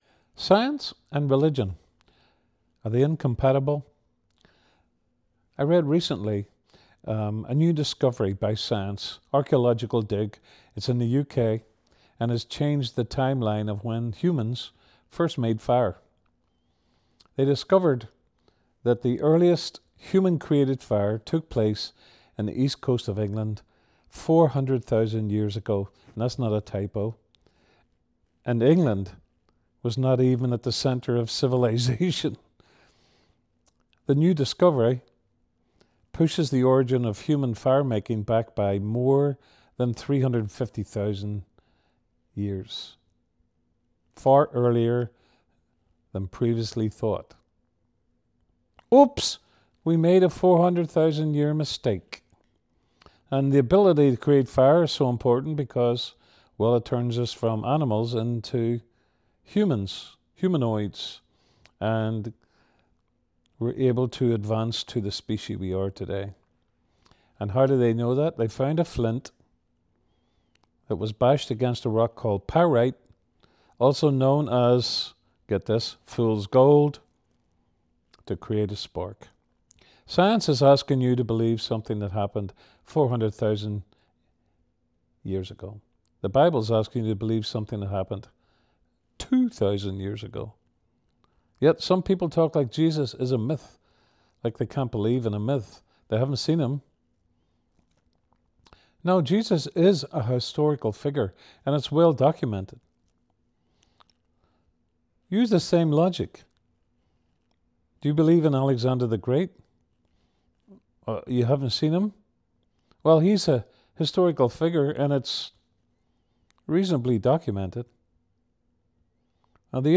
A Christmas devotional